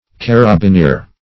Carabineer \Car`a*bi*neer"\ (k[a^]r`[.a]*b[i^]*n[=e]r"), n.